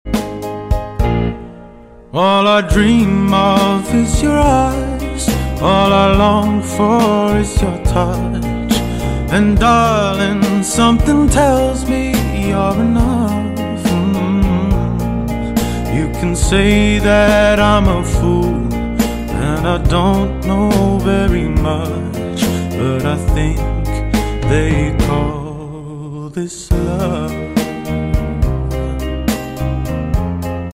AI Song Cover